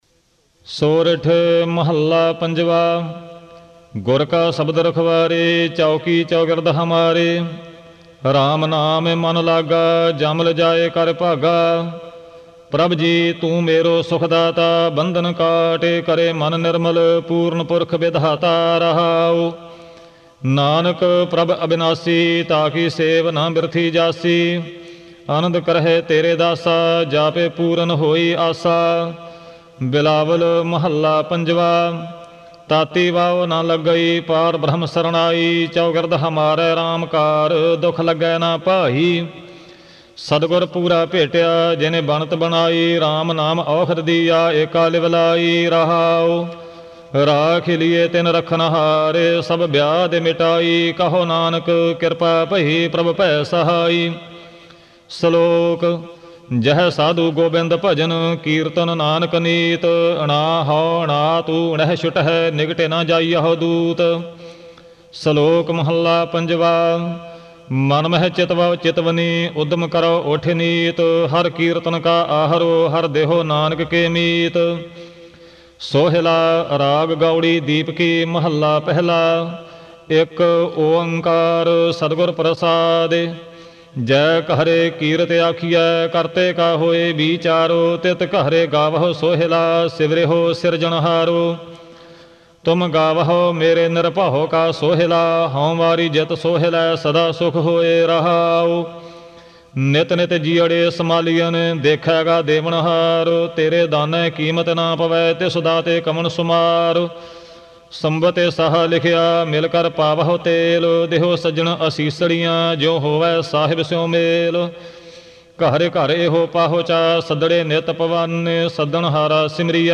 Genre: -Gurbani Ucharan